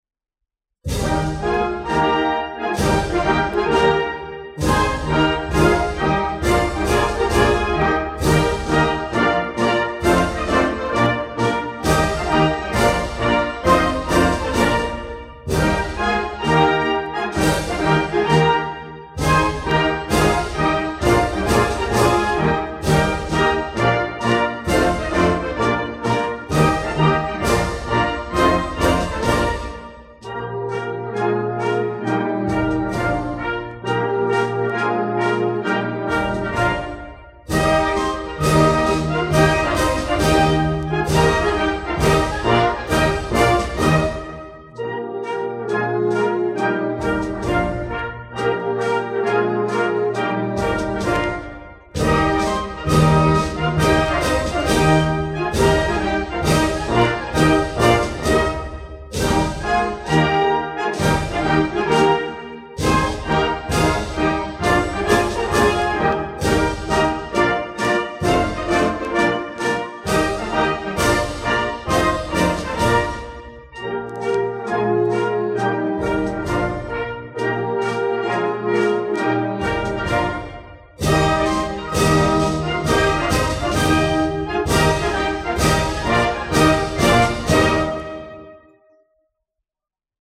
Marce militari - Marcia Reale - Regno delle Due Sicilie